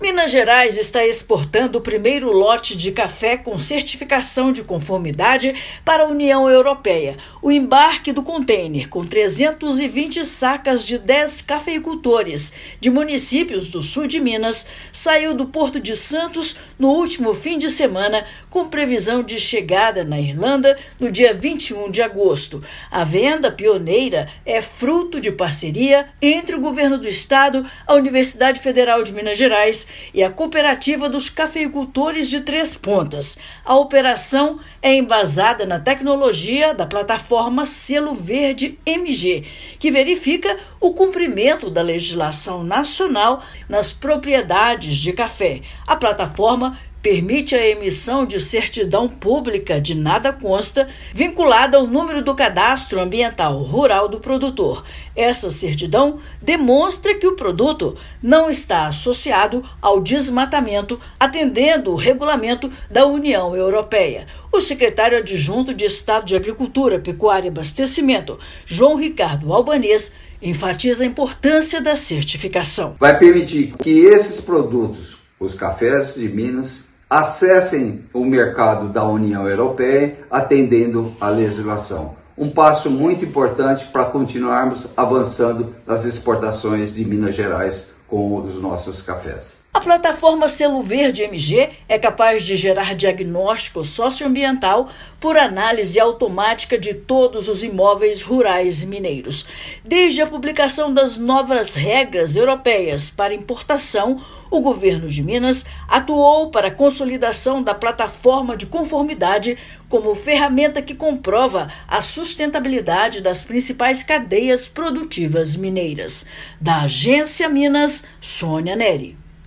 Operação emprega certidão pública e é fruto de parceria entre o Governo de Minas, Universidade Federal de Minas Gerais e a Cooperativa dos Cafeicultores de Três Pontas. Ouça matéria de rádio.